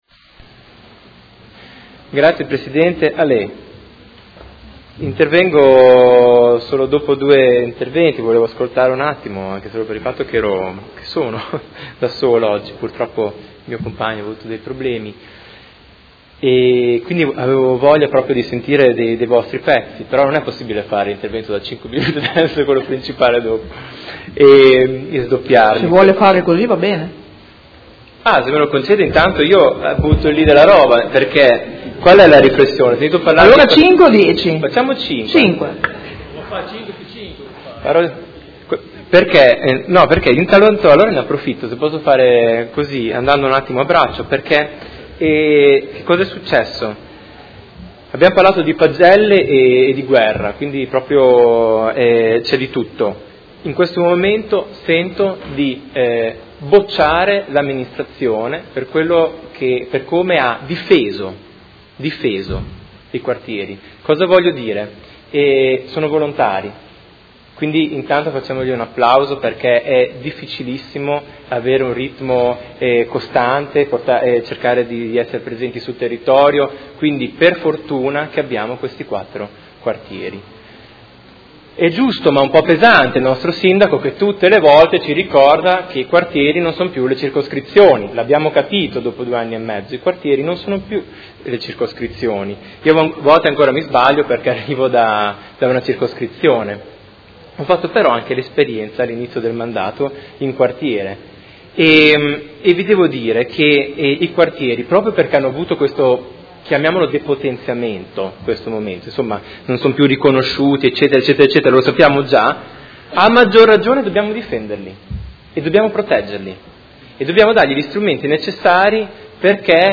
Seduta del 30/05/2016. Situazione dei Quartieri a Modena – dibattito